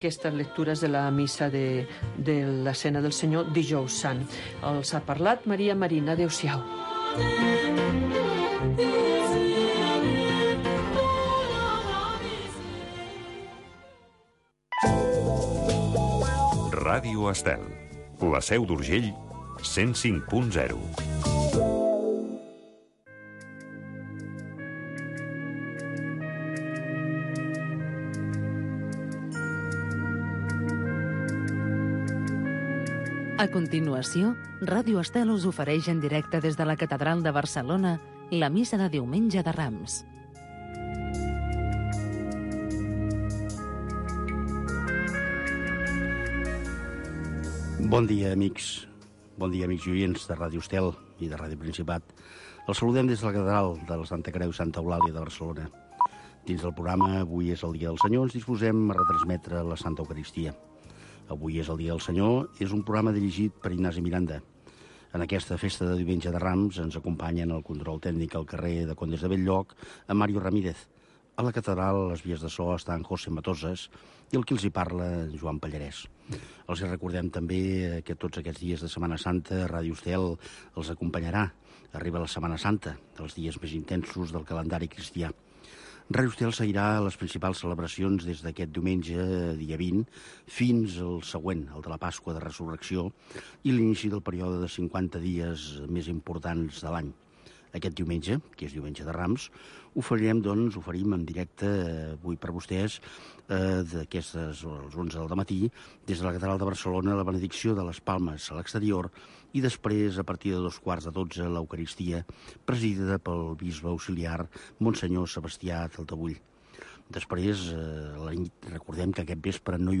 s de la catedral de Barcelona es retransmet tots els diumenges i festius la missa, precedida d’un petit espai d’entrevista